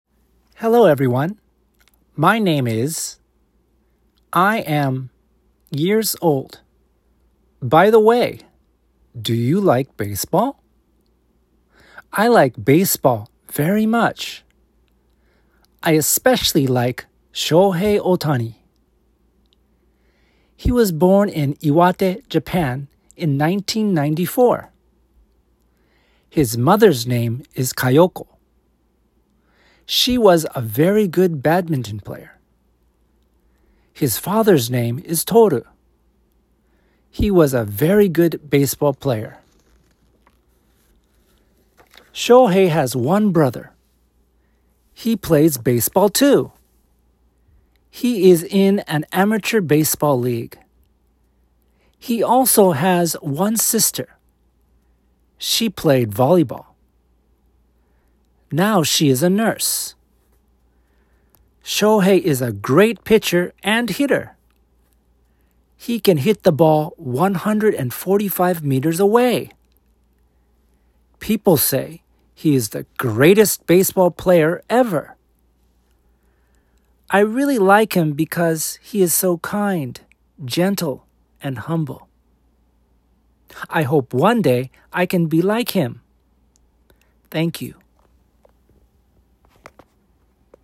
おてほん  (byネイティブ講師)
★男性の声バージョン